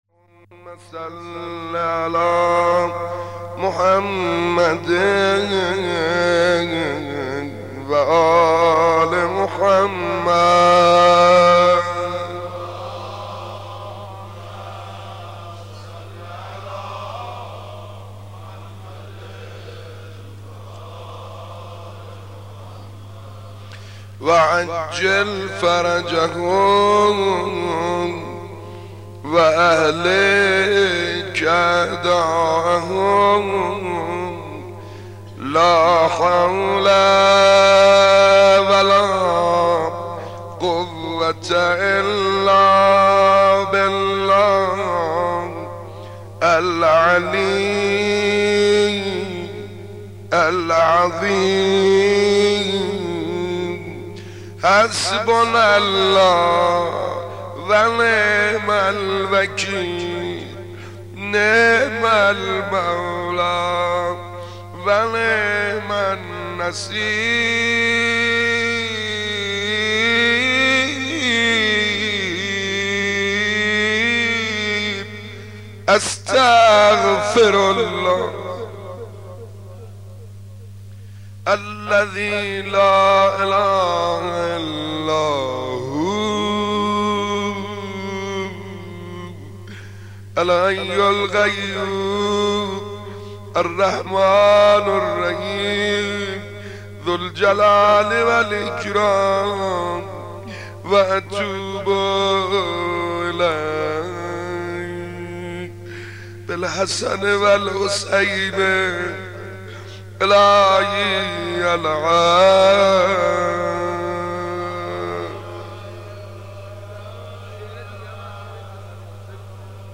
مناسبت : شب ششم محرم